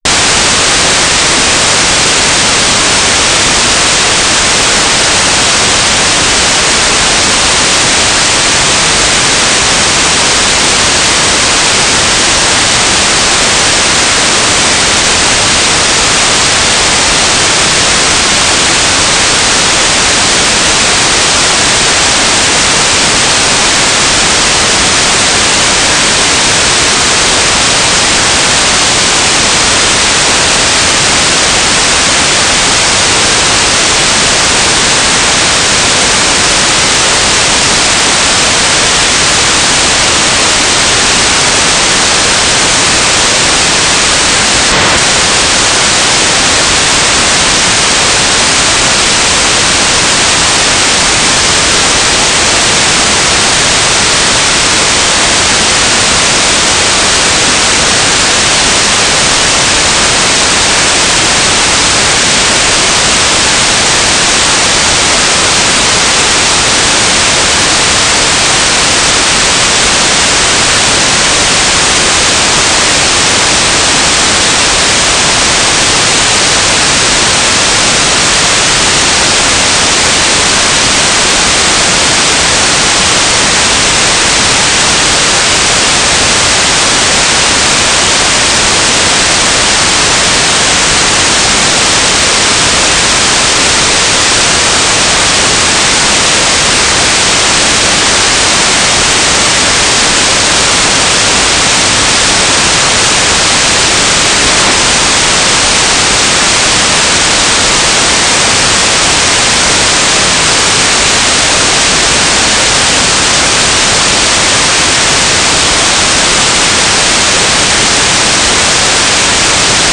"transmitter_description": "Mode U - FSK 9600 AX.25",
"transmitter_mode": "FSK",